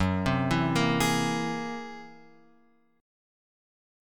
F#9b5 chord {2 3 2 3 x 4} chord